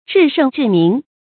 至圣至明 zhì shèng zhì míng
至圣至明发音